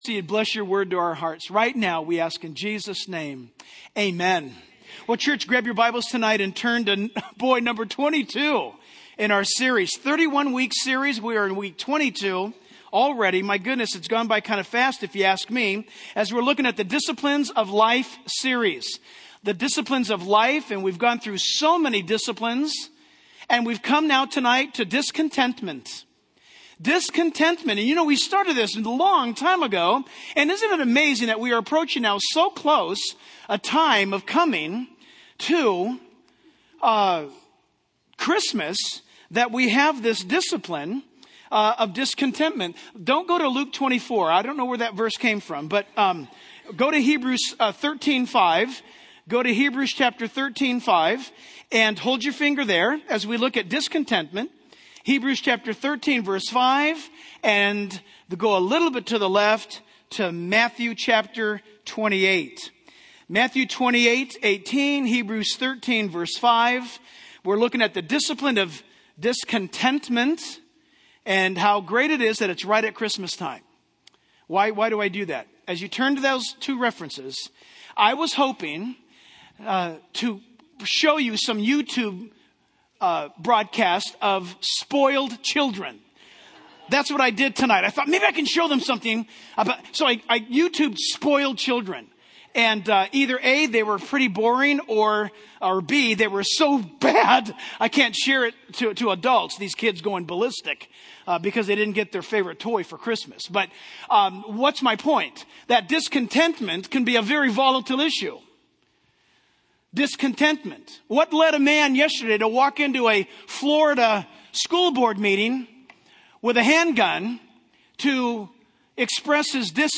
In this sermon, the speaker discusses the issue of discontentment and its negative effects. He starts by highlighting the causes of fights and quarrels, which stem from our desires that battle within us. The speaker then emphasizes the importance of being content and not coveting what others have, as stated in Hebrews 13:5.